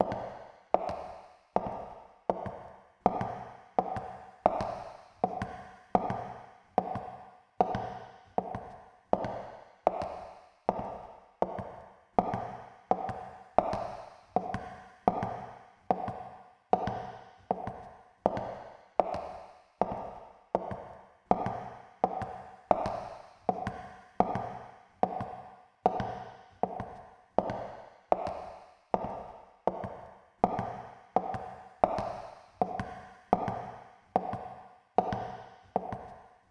反響する足音
人間 生活音
壁や床に響き渡る、反響する足音。閉鎖的な空間や、広々とした場所での孤独感を演出するのに最適です。